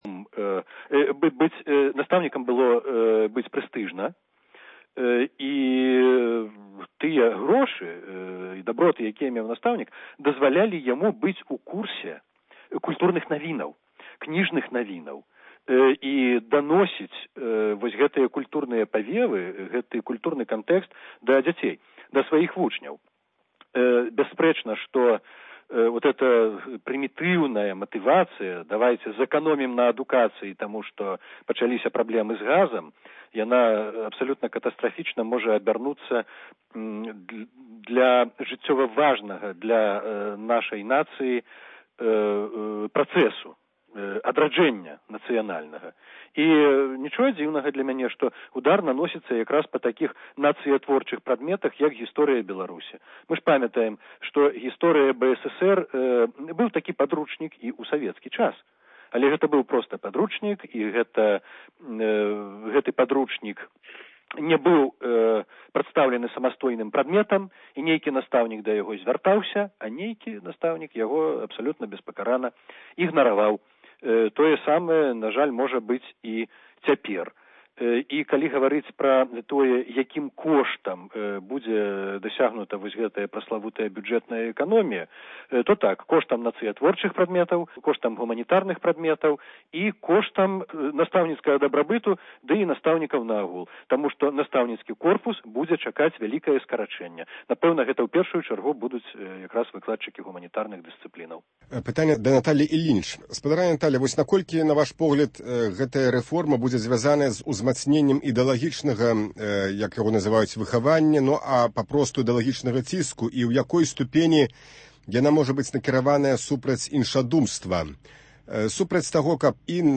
чытае фрагмэнт з аповесьці Васіля Быкава “Аблава”.